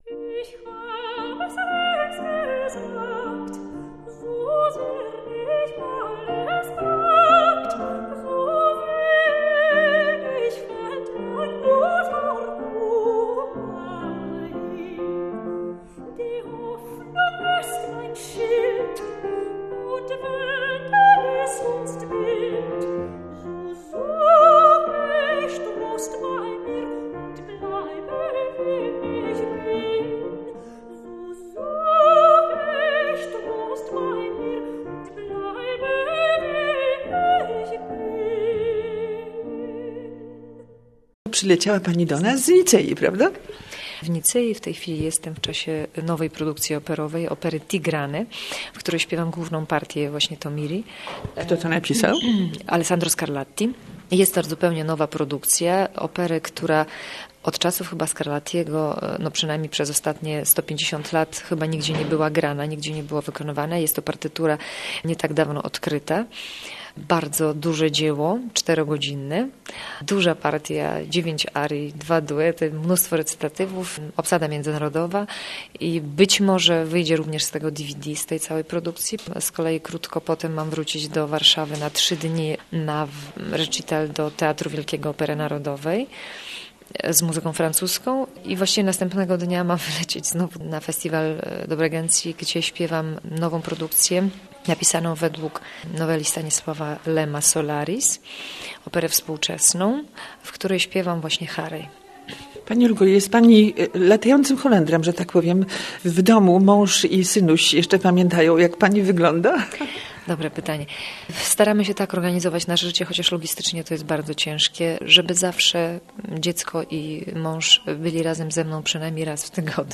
sopran
fortepian
Koncert zainaugurował V Festiwal Kultury "UKRAIŃSKA WIOSNA".